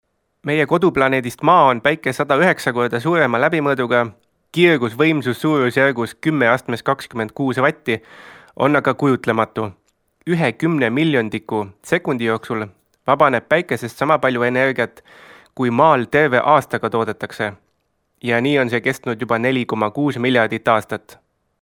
Estonian speaker, voice over, audio book narrator
Kein Dialekt
Sprechprobe: Industrie (Muttersprache):